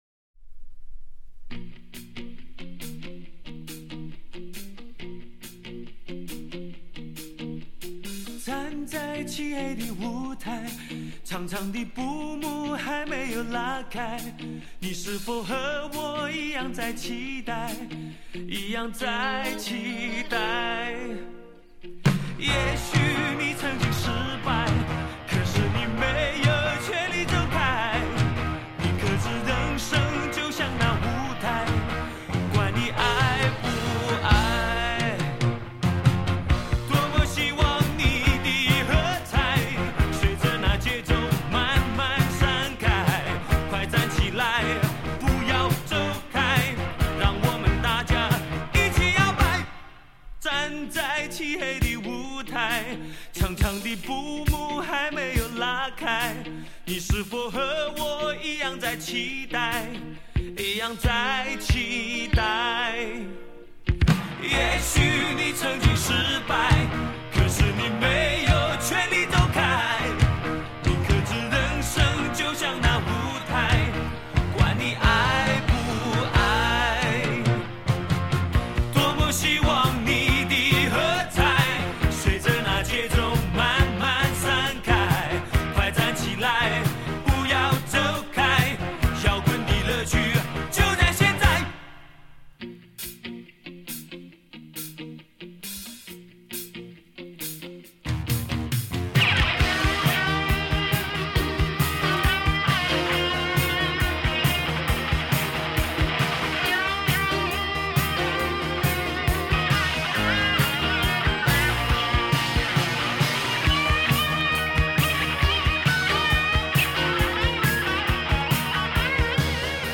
本专辑首次发行日为1984年3月，当年录音技术为类比录音，为尊重当年录音品质，不修饰，不增加声音内容，全部原音重现。
这张专辑的音乐形式听起来挺“摇滚”的，但主要限于音乐形式。
专辑的音乐，在实质上，还是更硬朗、更节奏化的台湾校园民歌的延伸。